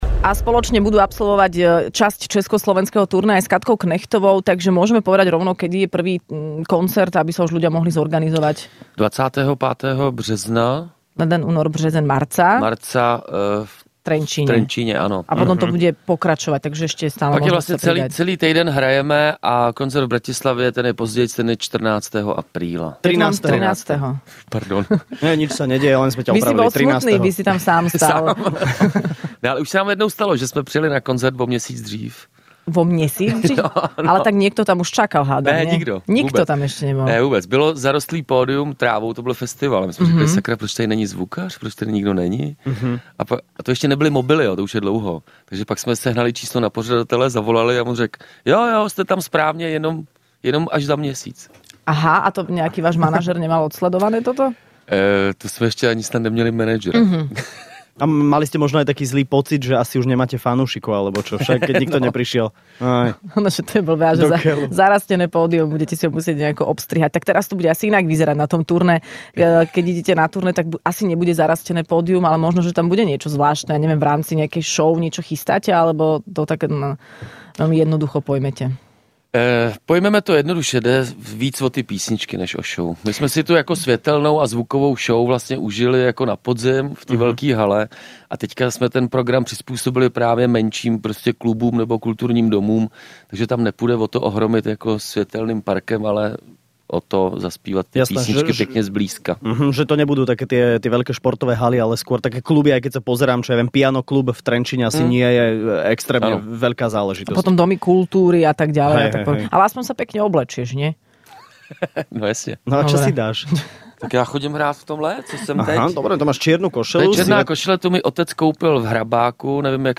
Skupina Chinaski prišla do Rannej šou predstaviť svoj nový album